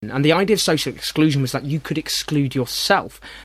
The second use is for emphasis, in which case they are stressed.
In the other three examples the pronouns are used emphatically.
324.-you-could-exclude-YOURSELF.mp3